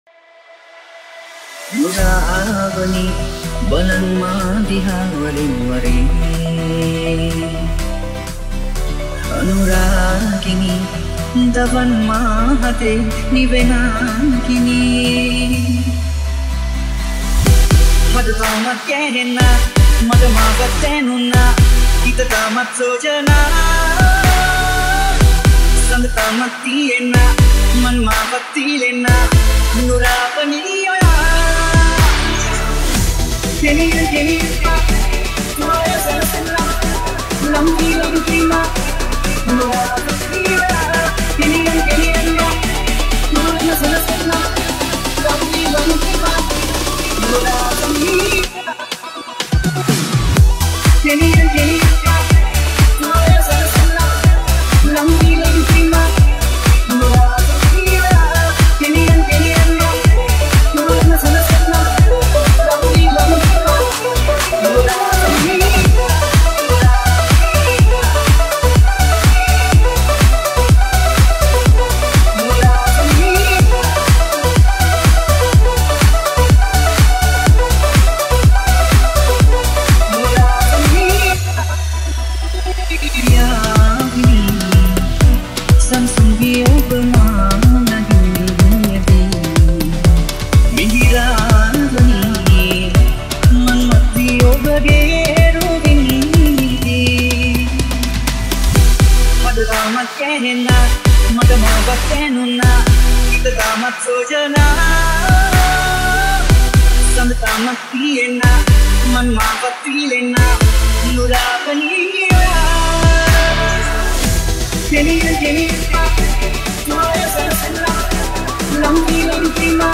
RemixSinhala Songs